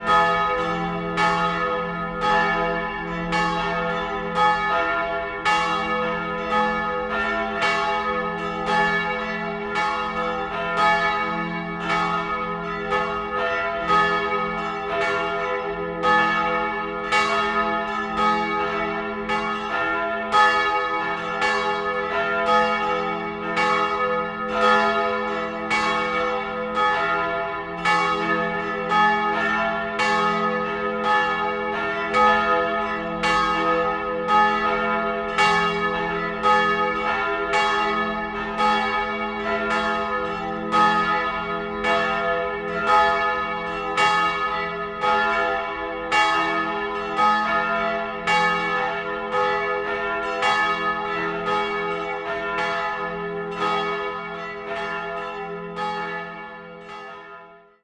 JM_AMB_EXT_Church Bell 02 - Calling for ceremonial
Bell Bells Cathedral Ceremonial Chime Church Ding Ring sound effect free sound royalty free Sound Effects